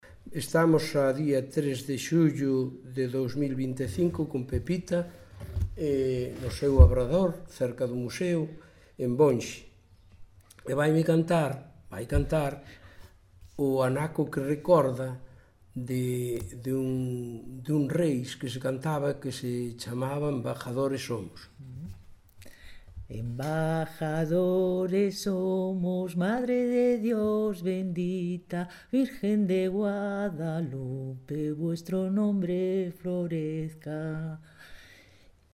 Tipo de rexistro: Musical
LITERATURA E DITOS POPULARES > Cantos narrativos
Lugar de compilación: Outeiro de Rei - Bonxe (San Mamede)
Instrumentación: Voz
Instrumentos: Voz feminina